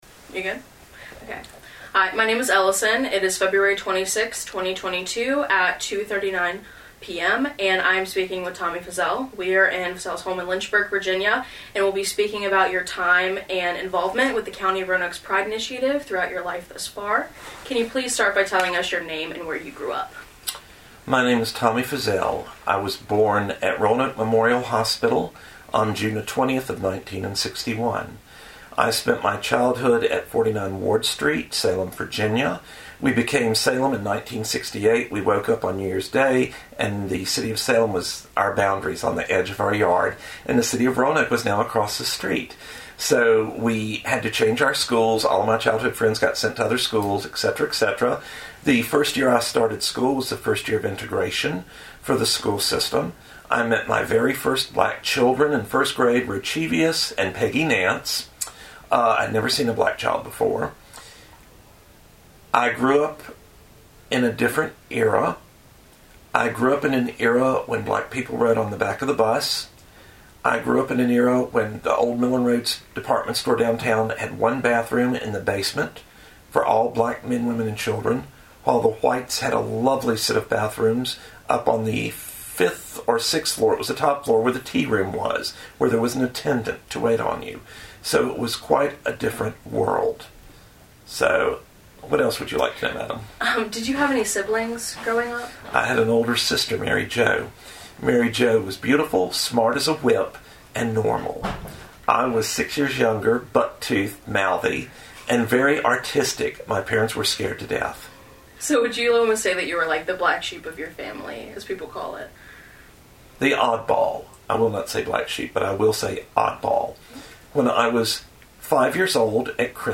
Oral History Interview
Location: Private residence